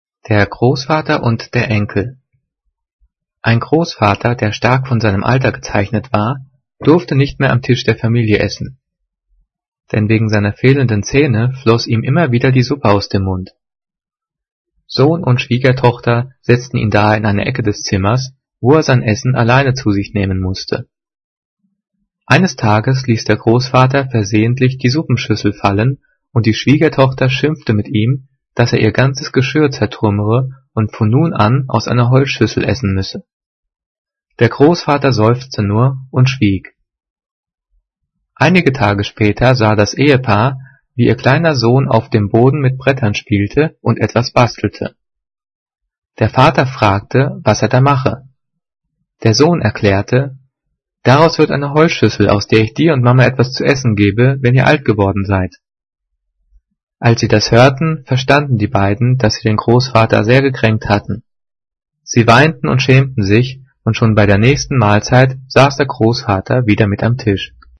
Gelesen:
gelesen-der-grossvater-und-der-enkel.mp3